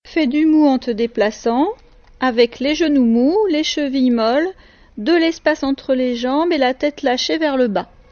Consigne donnée par la maîtresse: